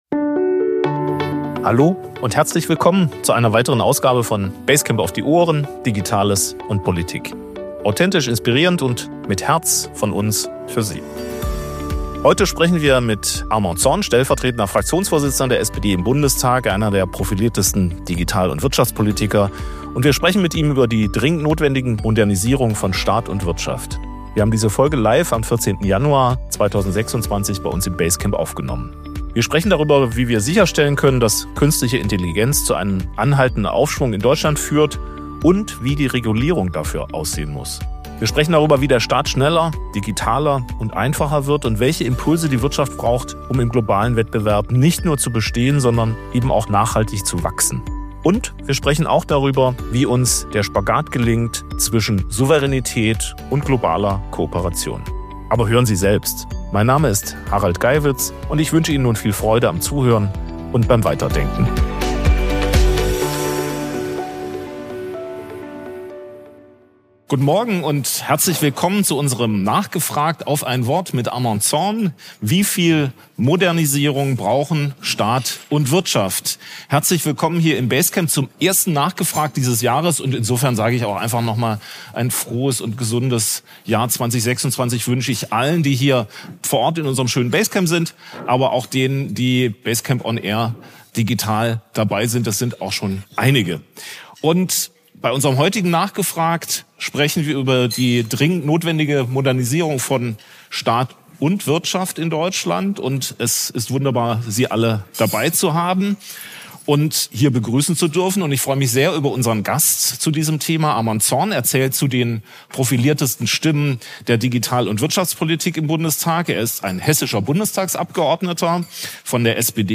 Wir sprechen heute mit Armand Zorn, stellvertretender Fraktionsvorsitzender der SPD im Bundestag, über die dringend notwendige Modernisierung von Staat und Wirtschaft.